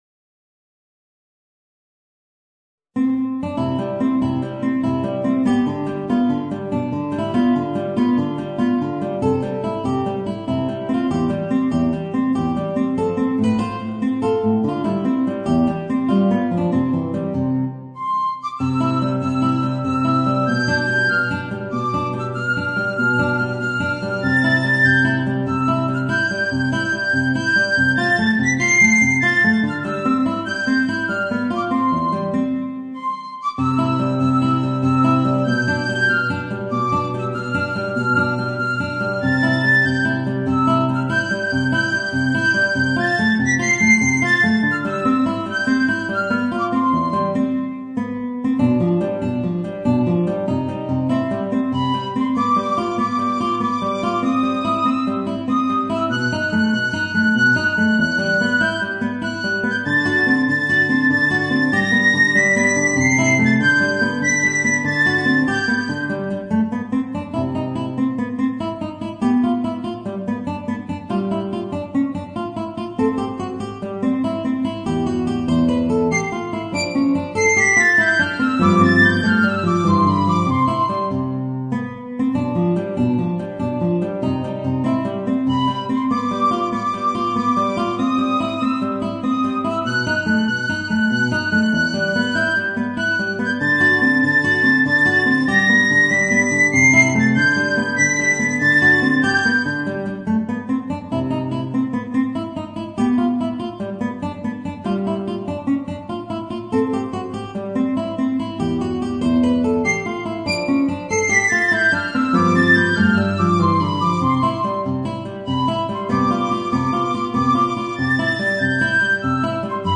Voicing: Piccolo and Guitar